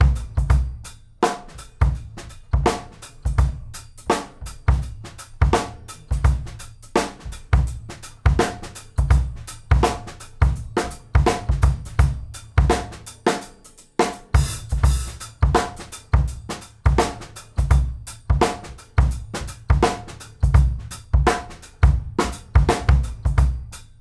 GROOVE 190IL.wav